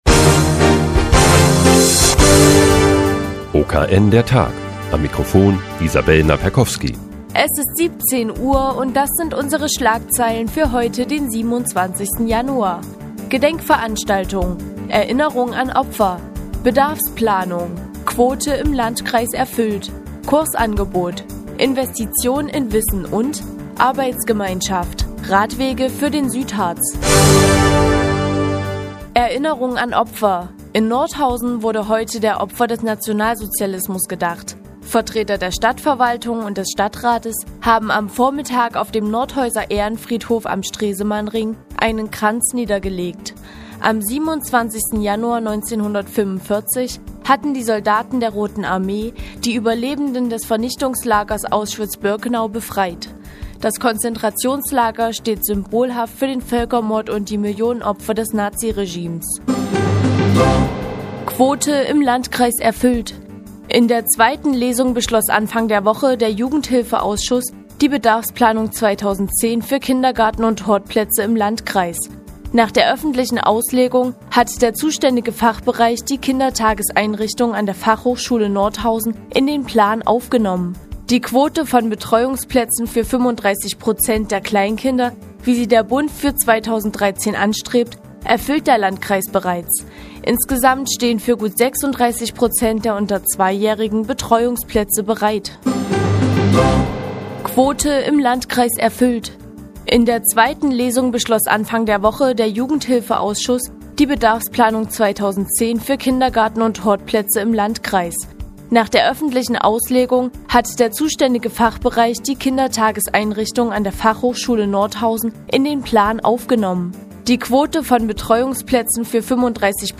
Die tägliche Nachrichtensendung des OKN ist nun auch in der nnz zu hören. Heute geht es um die neue Bedarfsplanung 2010 für Kindergarten- und Hortplätze sowie das neue Kursangebot der Volkshochschule Nordhausen.